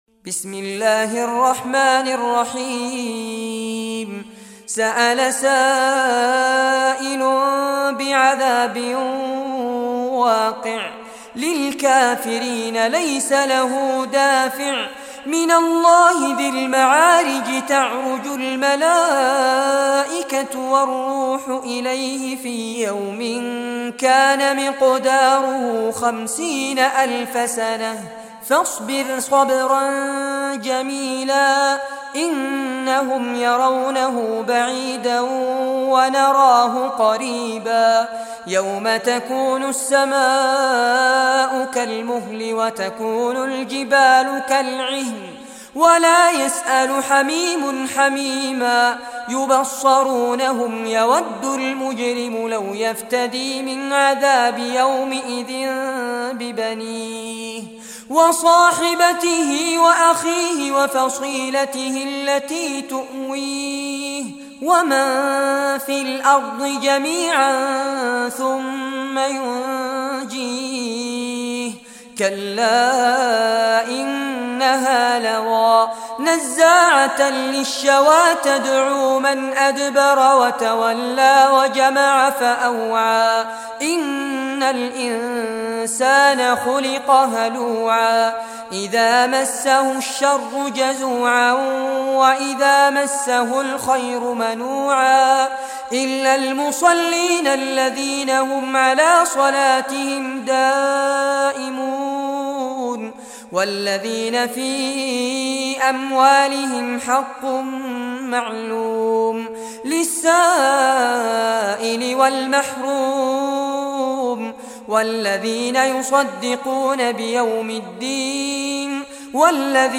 Surah al-Maarij Recitation by Fares Abbad
70-surah-maarij.mp3